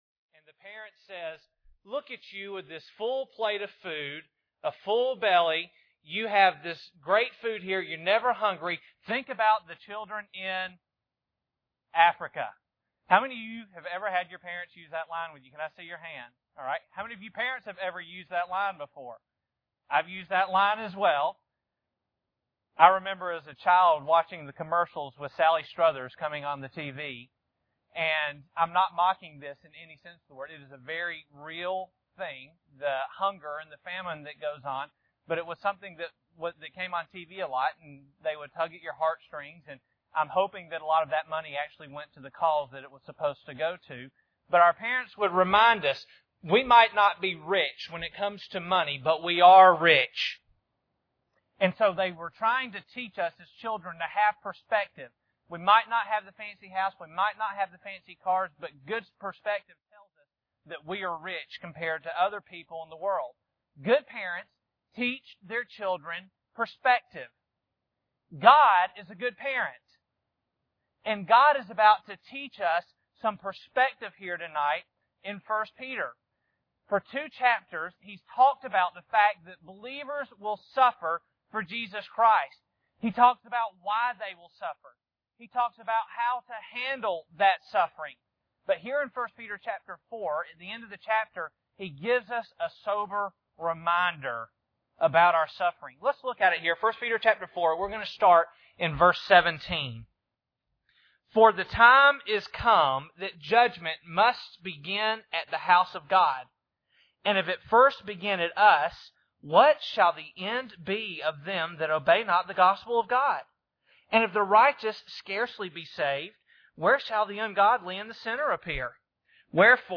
1 Peter 4:17-19 Service Type: Sunday Evening Bible Text